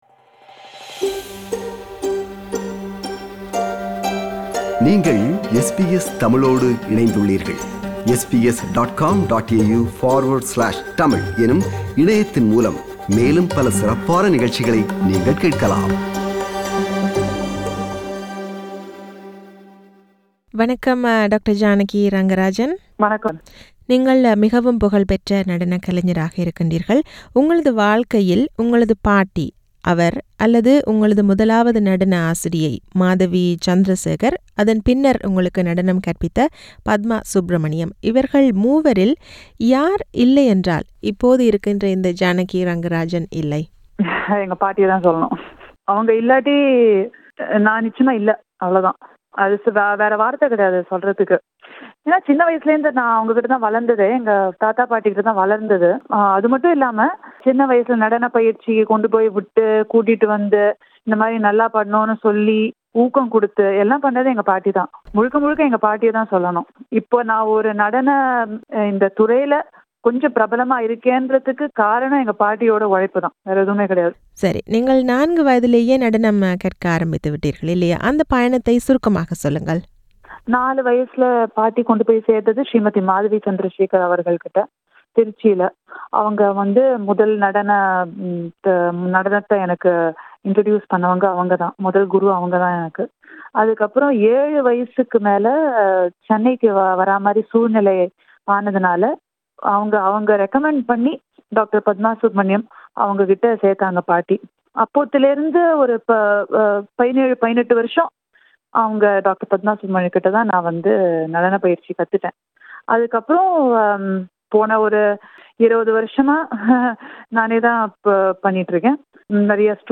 This is an interview with her.